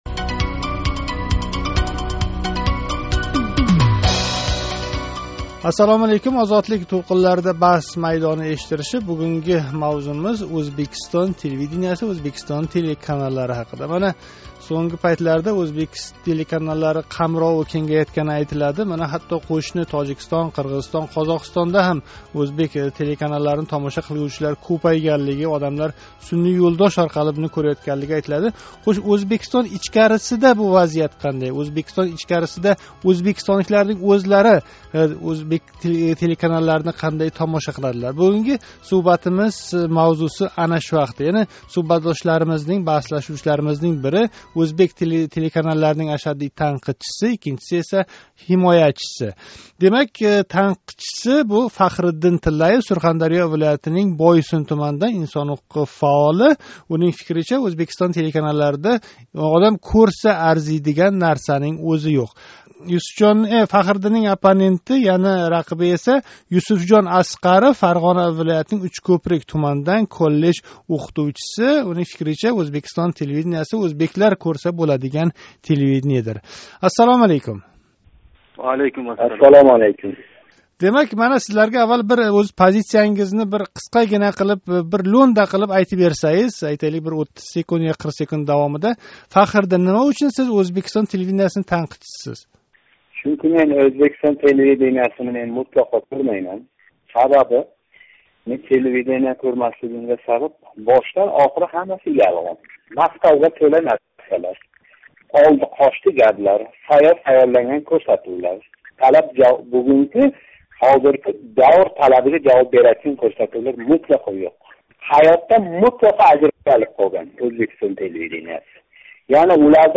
Ўзбекистон Миллий телерадиокомпаниясига қарашли телеканаллар кўрсатувлари сифати ҳақида унинг мухлиси ва танқидчиси баҳслашади.